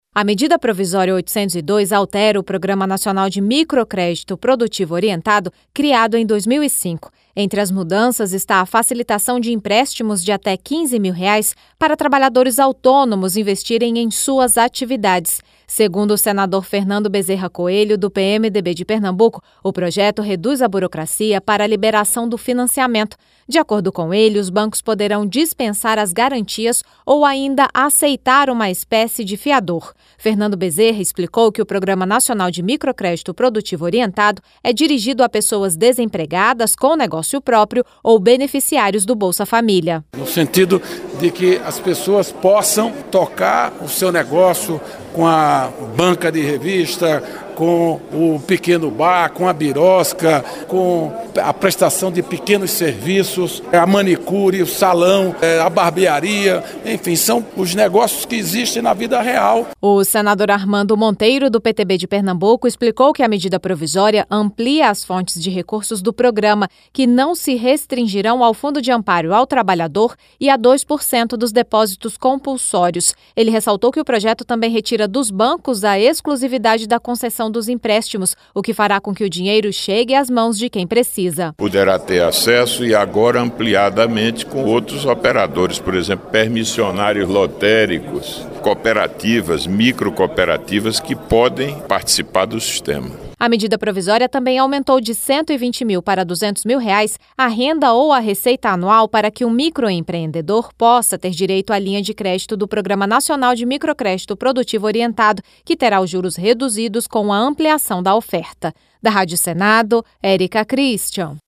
O presidente da comissão, senador Fernando Bezerra Coelho (PMDB-PE), explicou que o PNMPO é dirigido a pessoas desempregadas com negócio próprio ou beneficiários do Bolsa-Família. A reportagem